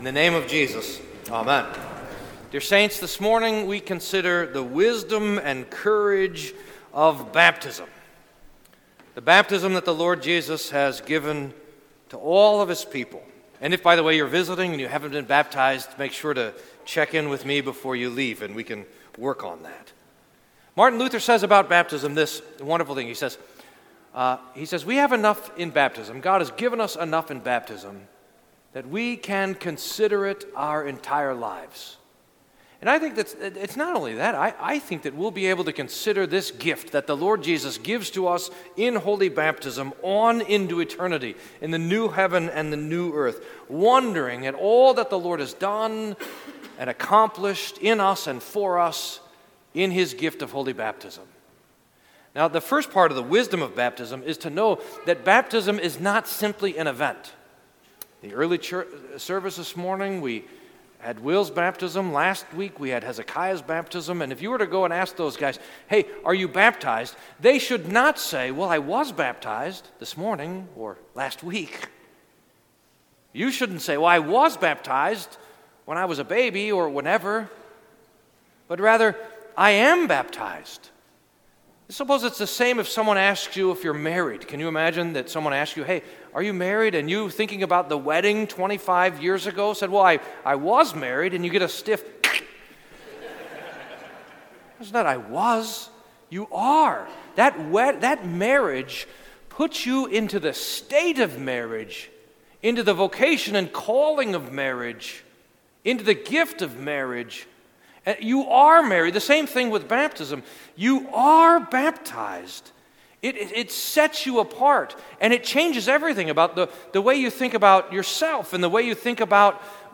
Sermon for the Baptism of Our Lord